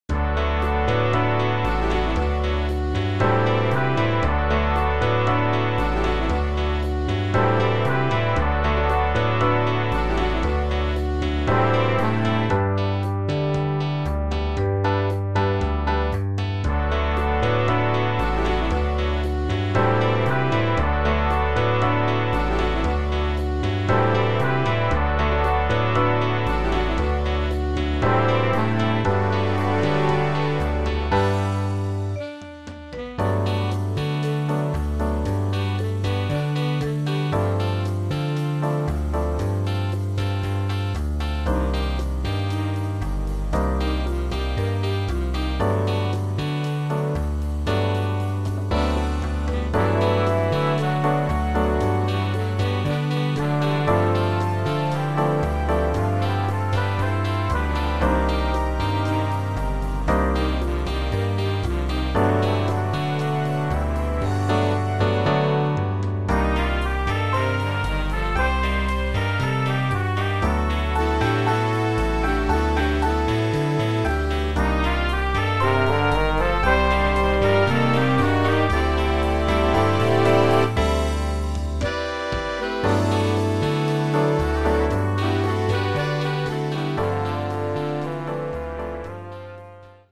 Jazz Band
This big band arrangement
Instrumentation is 5 saxes, 6 brass, 4 rhythm.